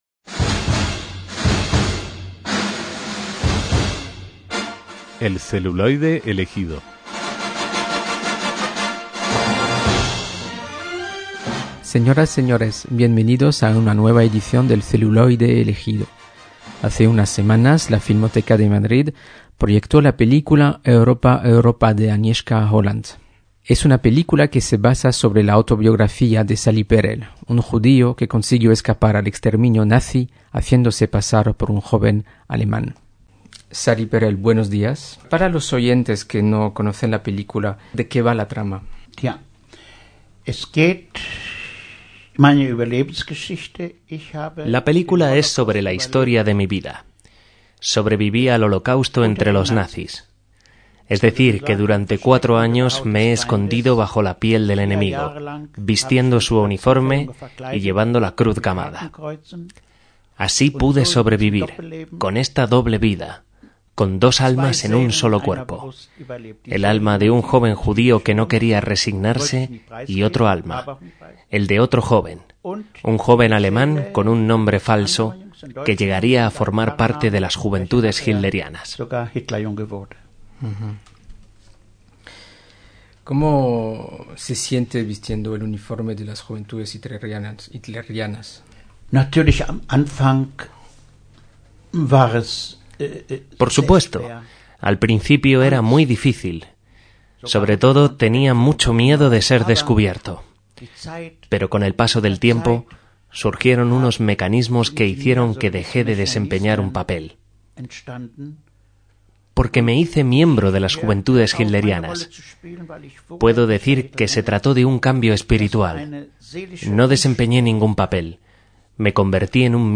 HOMENAJE - El pasado 2 de febrero de 2023 fallecía a los 97 años de edad Salomón (Shlomo o Sally) Perel, que, siendo niño, logró sobrevivir al Holocausto en la Alemania nazi haciéndose pasar por ario y cuya vida inspiró el argumento de la película "Europa Europa" de Agnieszka Holland. En 2009, con motivo de la traducción al español de su libro, visitó nuestro país y Radio Sefarad, donde pudimos entrevistarle.